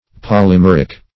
Polymeric \Pol`y*mer"ic\, a. [Poly- + Gr.